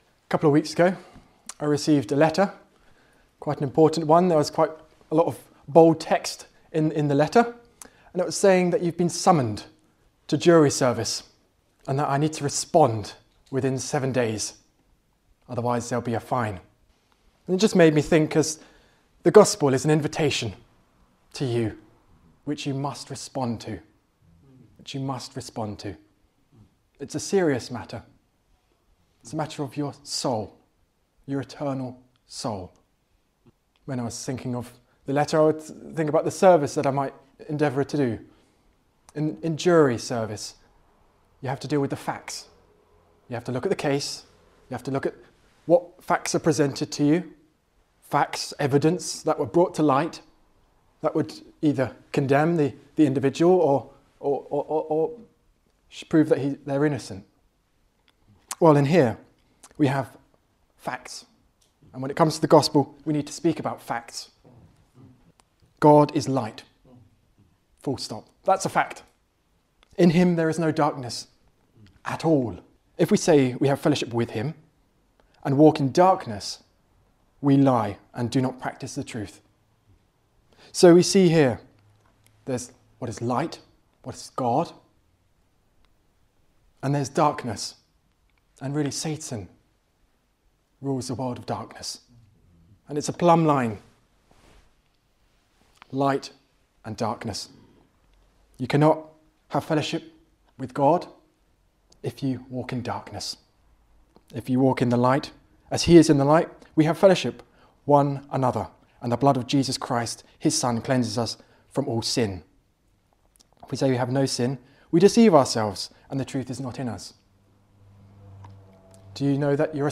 This message lays out the essential elements of the Good News—God's love, humanity's need, Christ's sacrifice, and the call to respond. Clear, compelling, and rooted in Scripture, this preaching challenges believers and seekers alike to grasp the reality of what Jesus has done and why it matters for eternity.